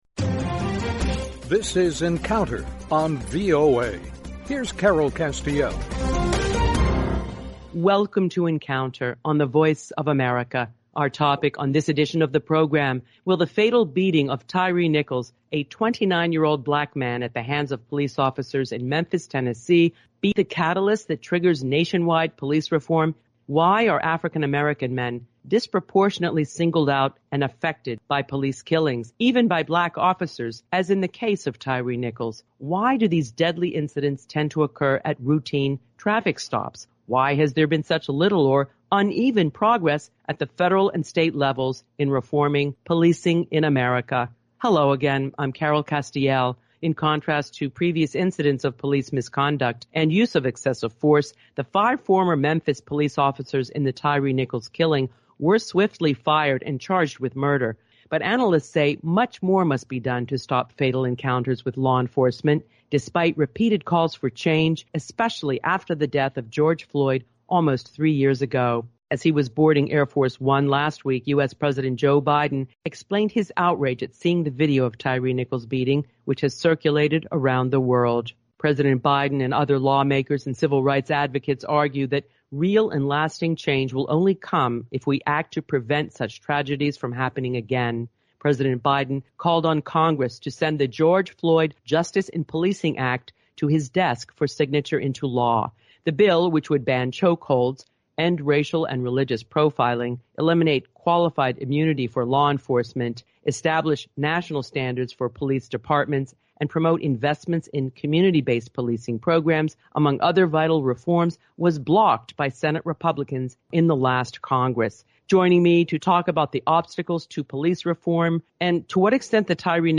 talks with law enforcement experts